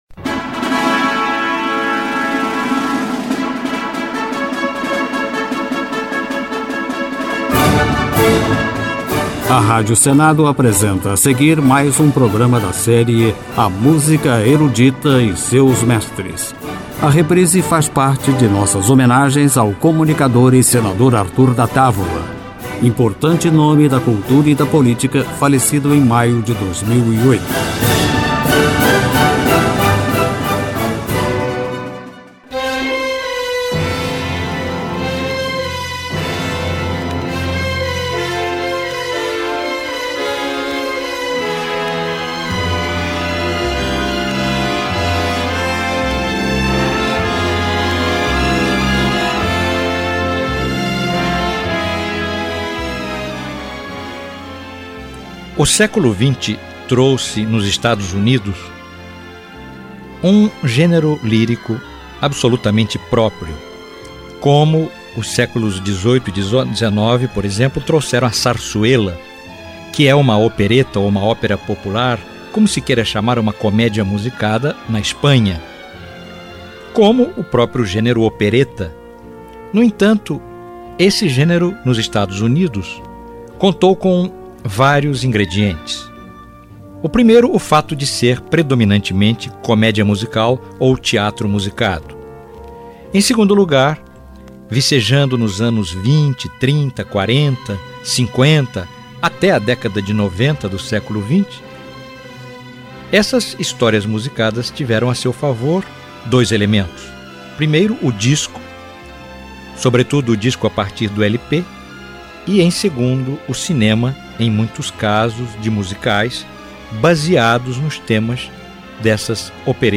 comédia musical
soprano
tenor